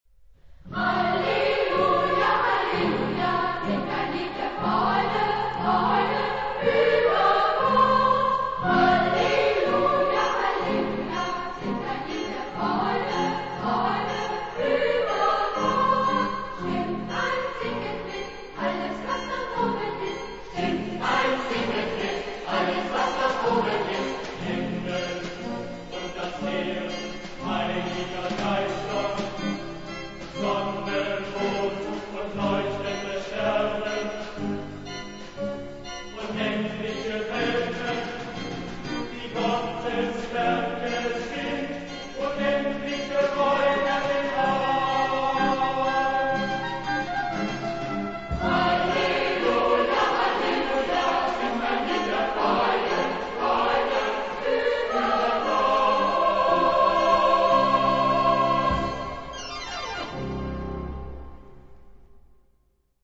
Lied ; Sacré
rythmé ; vivant
SATB + Gemeinde (4 voix mixtes )
Orgue (1) ou Clavier (1) ; Instruments (ad lib)
Tonalité : libre